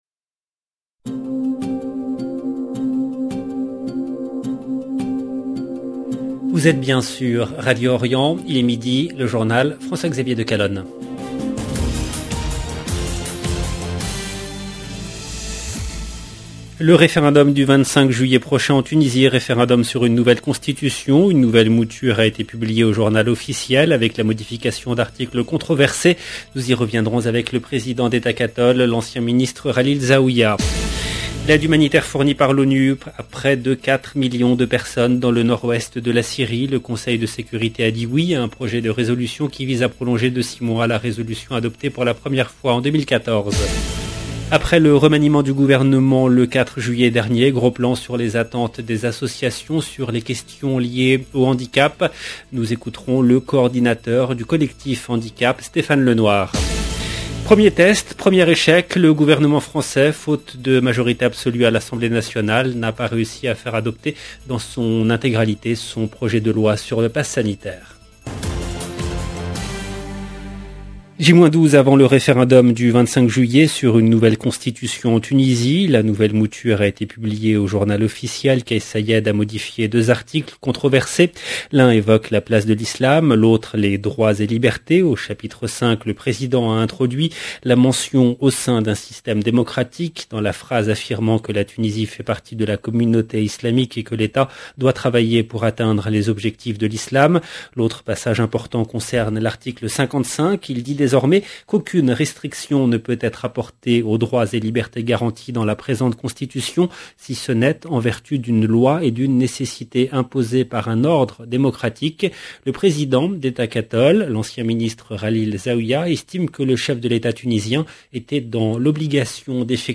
LB JOURNAL EN LANGUE FRANÇAISE